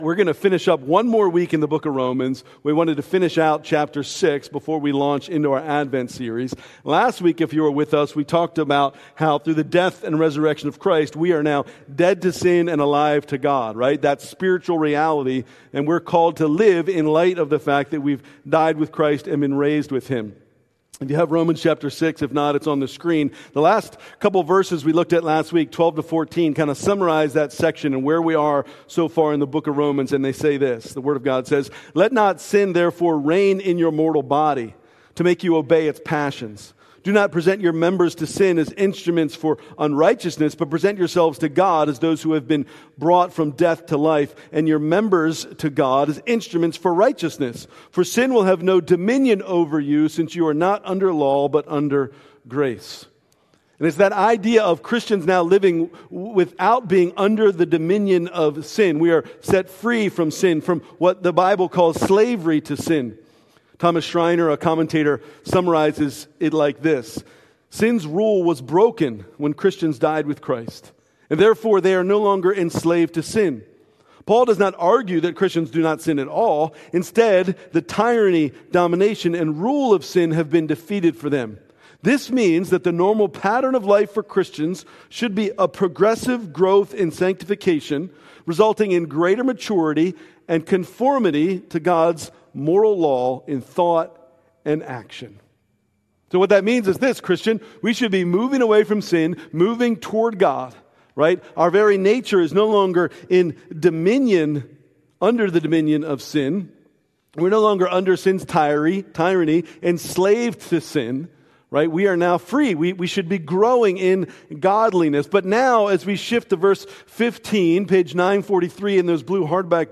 November 30, 2005 Worship Service Order of Service: